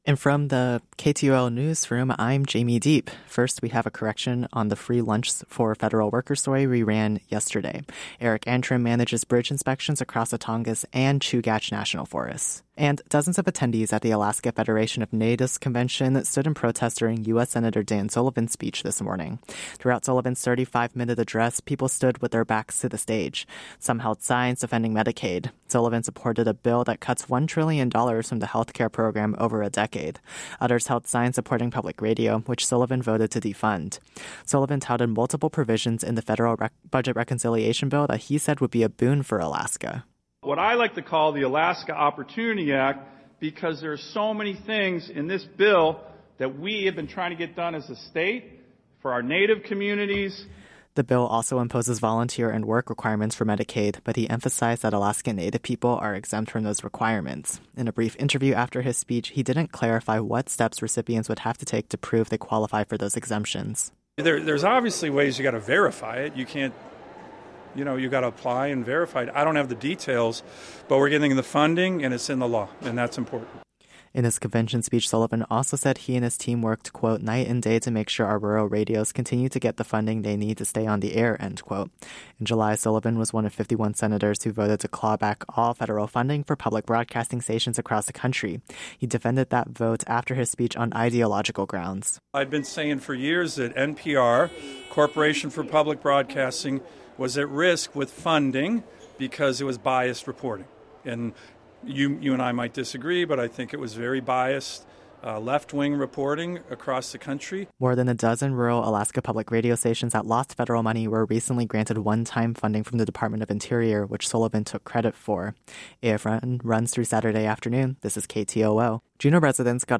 Newscast – Friday, Oct. 17, 2025 - Areyoupop